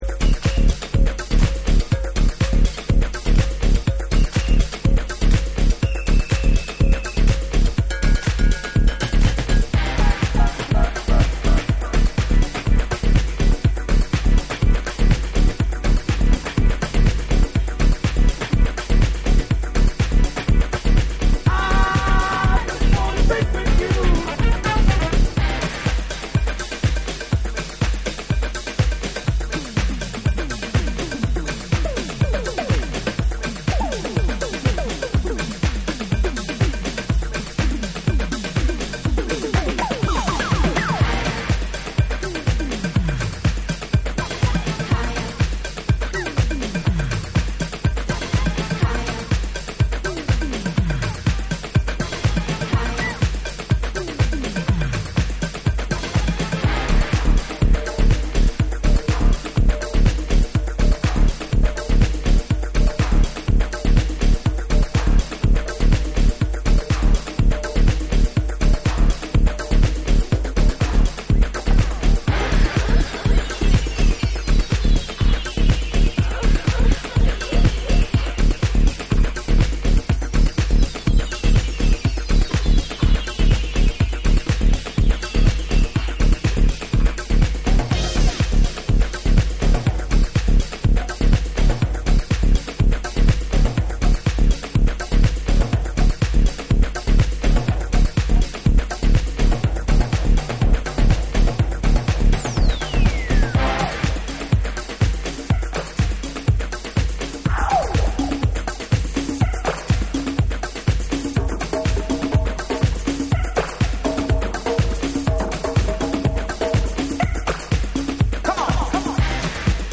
様々なネタを織り交ぜ展開するカット・アップ・ディスコ
スピリチュアルな鍵盤が絡んだディープ・メディテーティヴ・ナンバー
TECHNO & HOUSE / JAPANESE